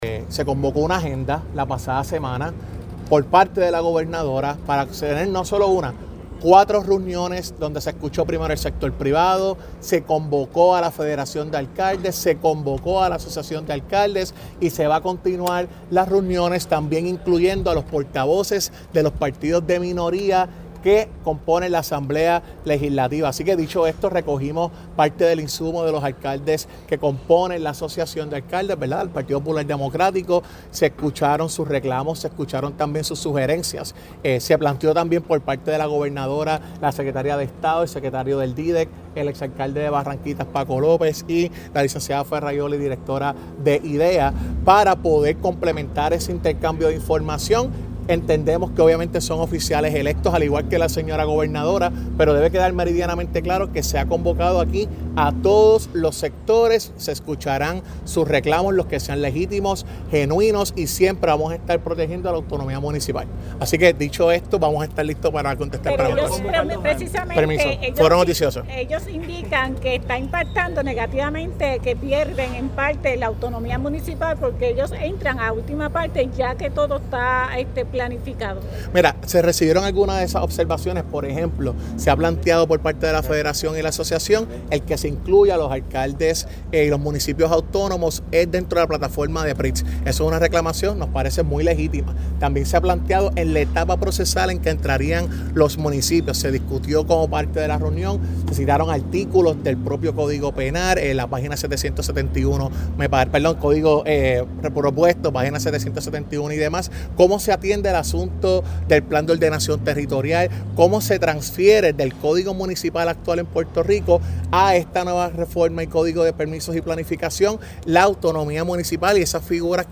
“El proyecto no le quita a los municipios su jerarquía “, dice el titular de Asuntos Públicos tras reunión con la Asociación de Alcaldes (sonido)